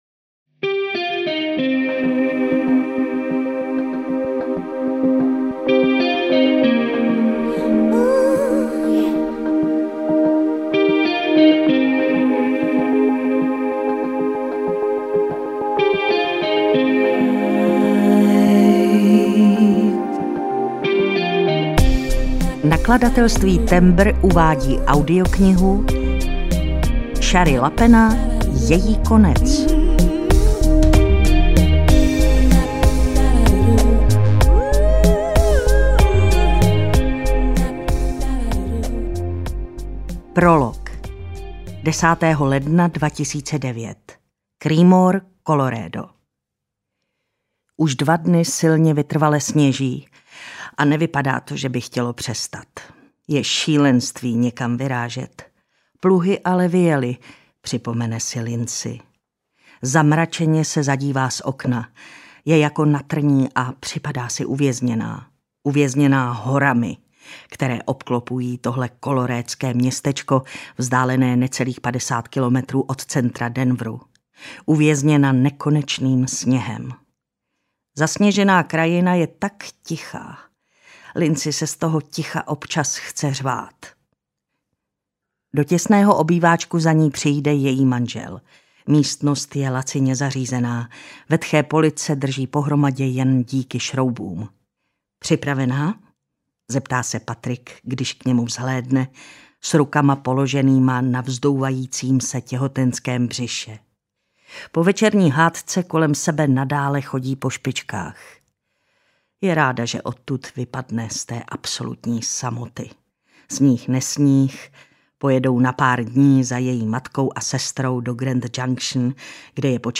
Její konec audiokniha
Ukázka z knihy